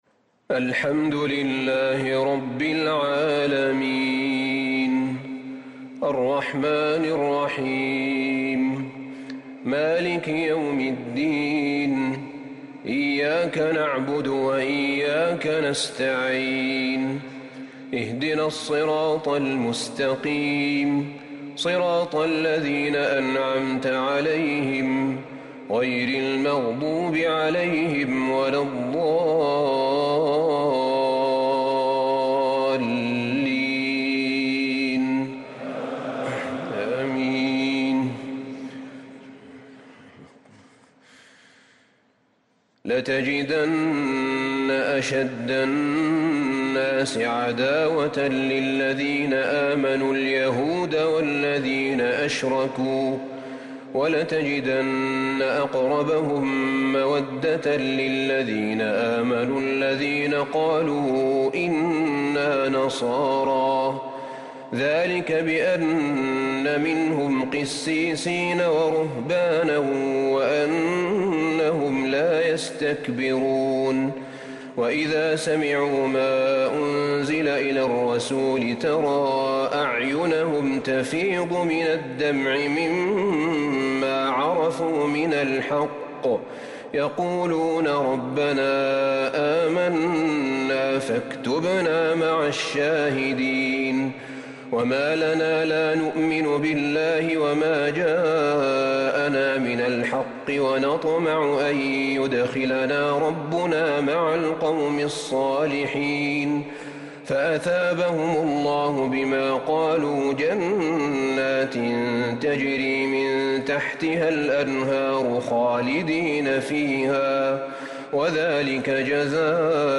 تراويح ليلة 9 رمضان 1444هـ من سورتي المائدة {82-120} و الأنعام {1-36} | Taraweeh 9st night Ramadan 1444H Surah Al-Ma'idah and Al-Ana'am > تراويح الحرم النبوي عام 1444 🕌 > التراويح - تلاوات الحرمين